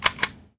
R - Foley 157.wav